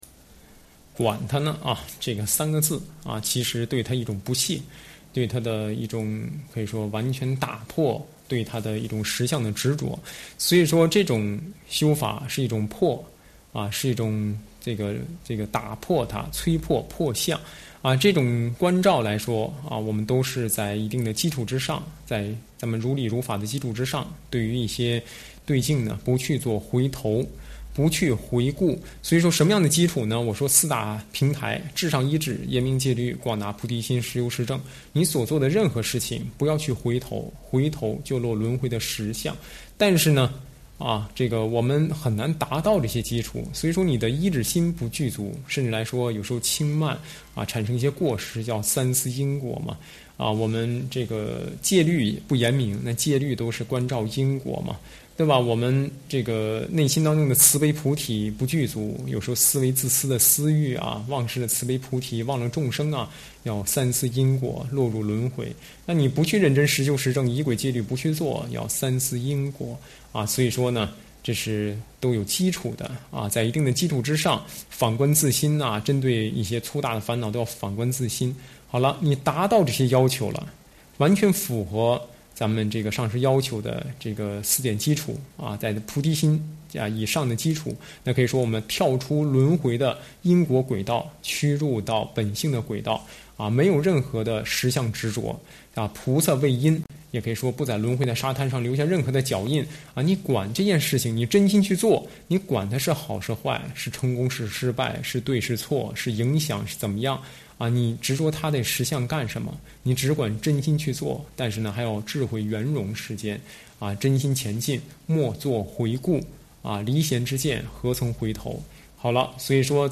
随笔开示
上师语音开示